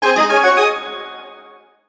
Achievement Unlock Sound Effect Free Download